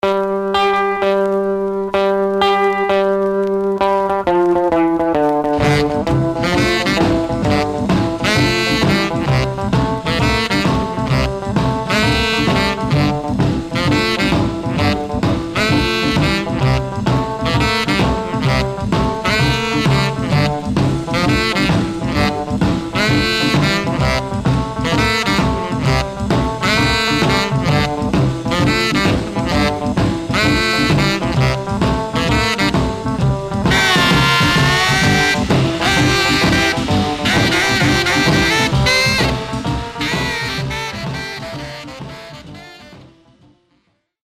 Surface noise/wear
Mono
R&B Instrumental Condition